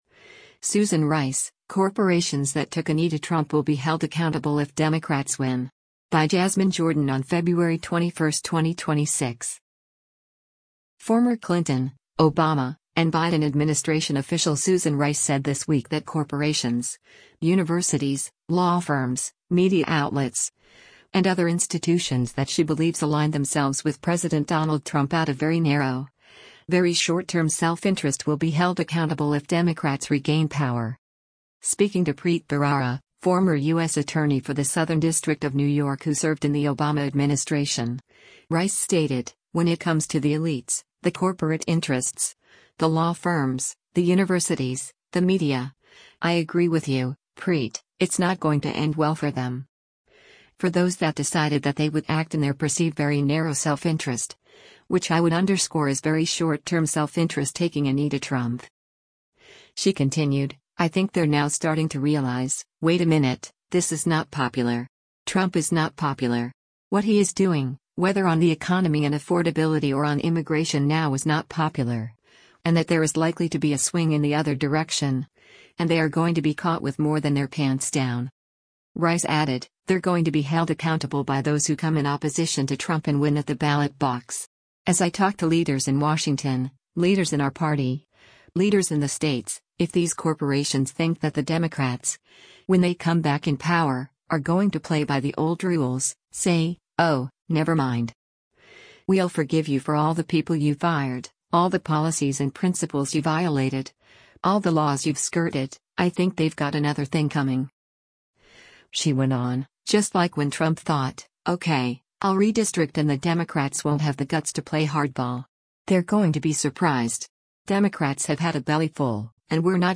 Rep. Derrick Van Orden (R-WI) reacted to the podcast clip, posting, “She is calling for communism in the most gentle way”: